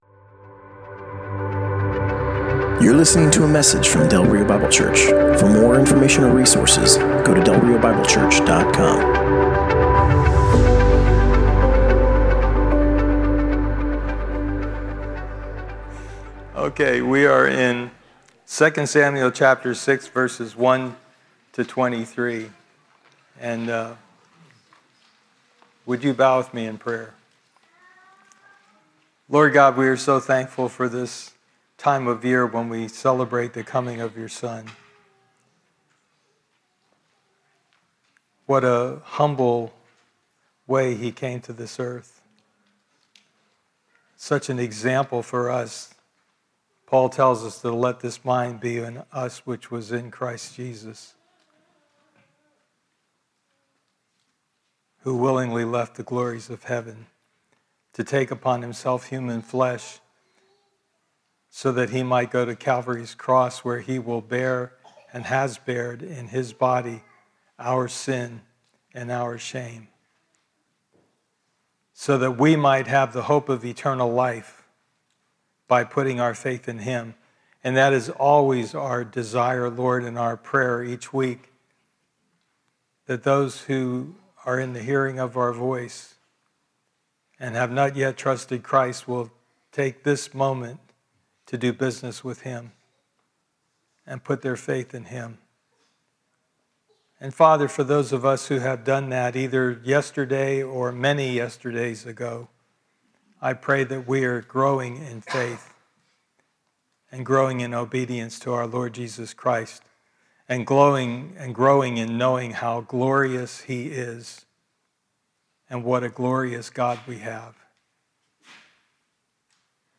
Passage: 2 Samuel 6: 1-23 Service Type: Sunday Morning